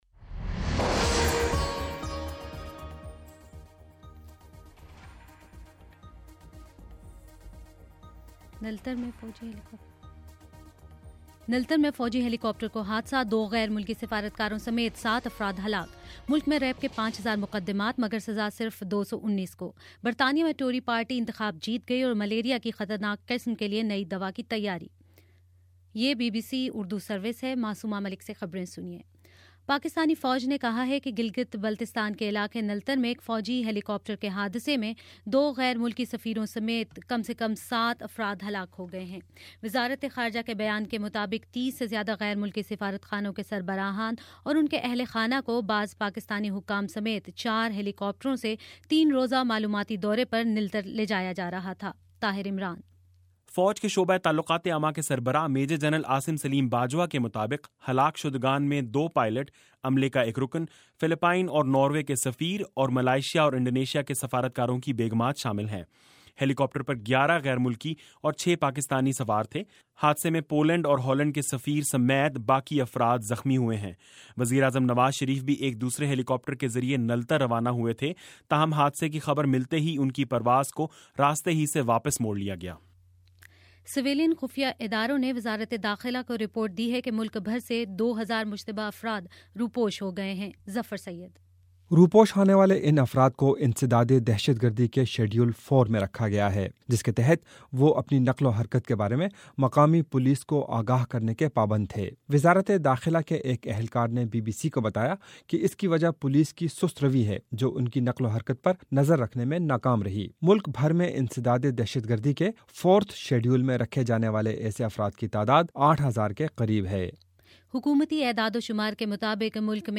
مئ 8: شام سات بجے کا نیوز بُلیٹن
دس منٹ کا نیوز بُلیٹن روزانہ پاکستانی وقت کے مطابق شام 5 بجے، 6 بجے اور پھر 7 بجے۔